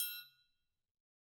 Triangle3-HitM_v2_rr2_Sum.wav